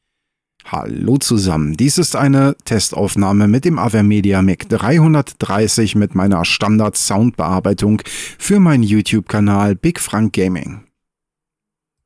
Hier eine Standard Aufnahme wie ich sie für meinen Gaming Channel bearbeite, bereinige und verstärke:
Keinerlei Hintergrundgeräusche werden störend mit aufgenommen, wobei es schon sehr ruhig war als die Aufnahmen gemacht worden sind.
Avermedia-MIC-330-Standard-Bearbeitung.mp3